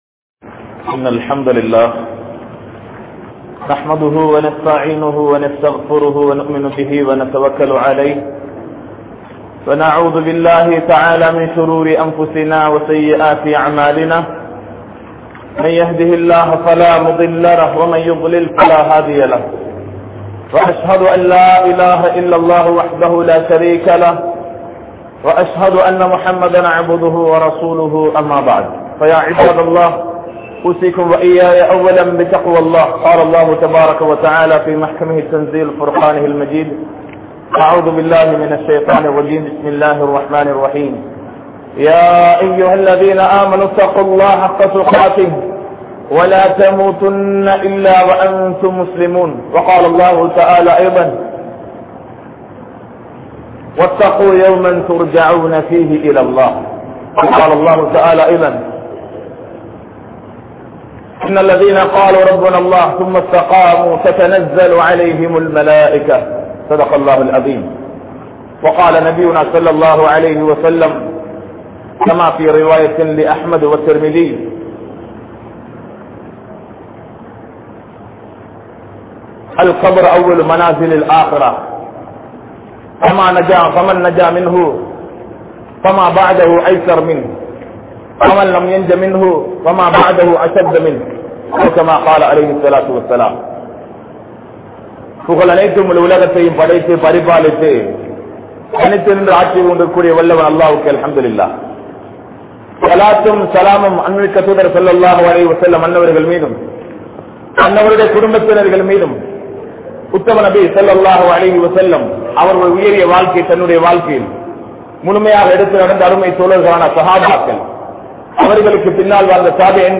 Mannaraien Muthal Iravu ( மண்ணறையின் முதல் இரவு) | Audio Bayans | All Ceylon Muslim Youth Community | Addalaichenai
Kandy,Malay Jumua Masjith